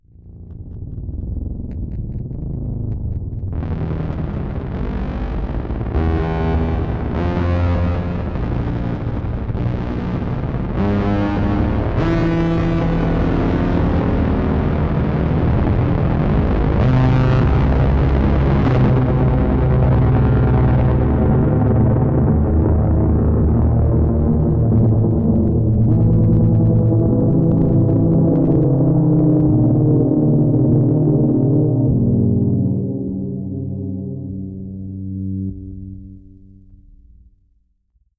7 - Noise Drone
7_NoiseDrone.wav